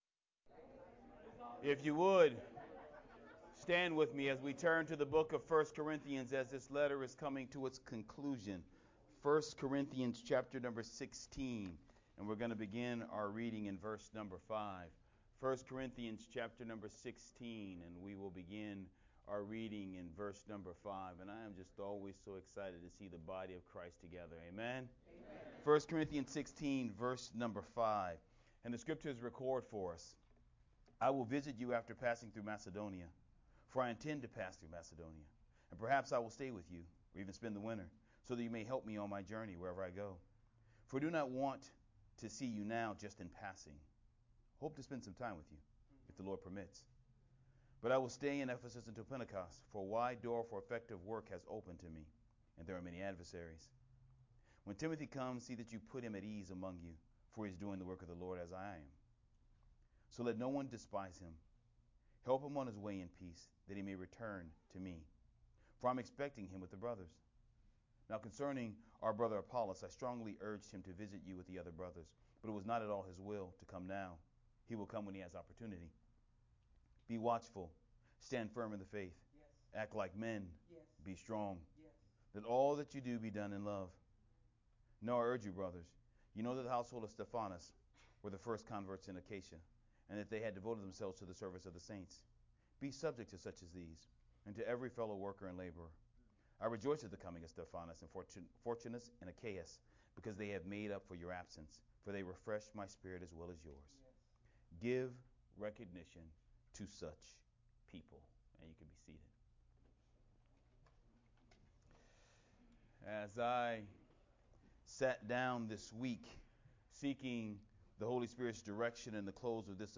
Sermon From 1st Corinthians 16:5-18